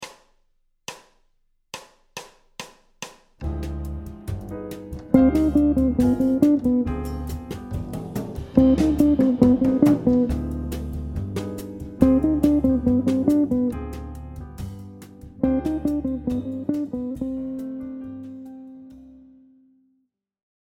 Phrases – cadence ii Ø . V7±9 en mineur
Phrase 01 : La phrase est jouée sur une seule corde et démarre par la Tonique de la Tonalité mineure.